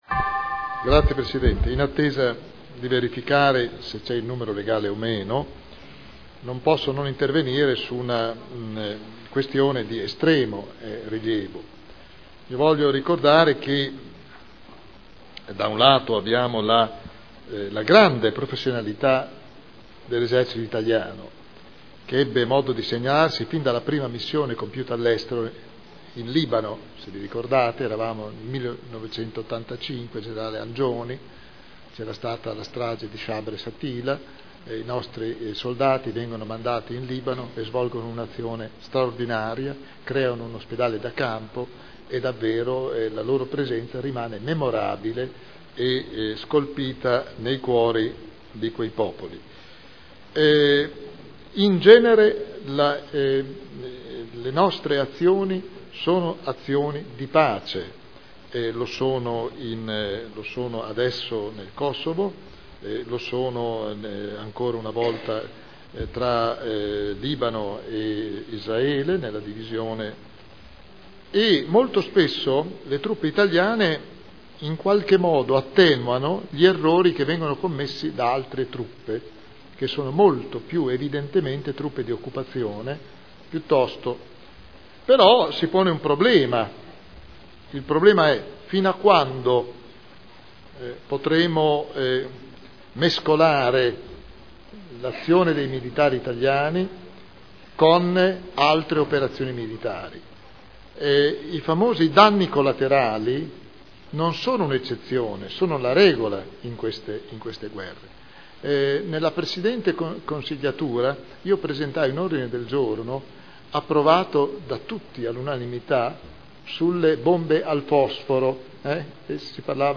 William Garagnani — Sito Audio Consiglio Comunale